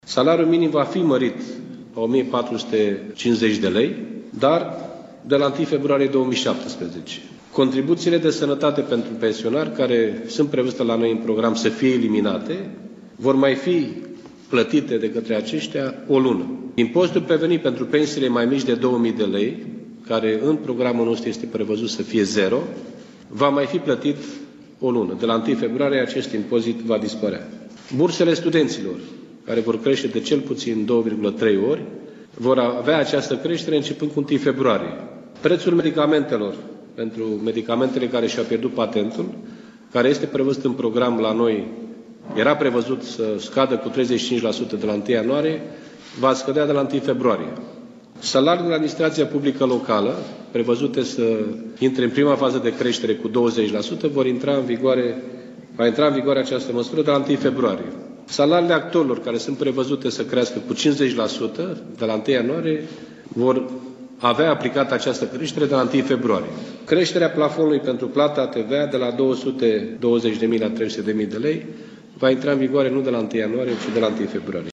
Președintele social-democraților, Liviu Dragnea, a susținut, astăzi, o conferință de presă alături de co-președintele ALDE, Călin Popescu Tăriceanu și de premierul desemnat, Sorin Grindeanu.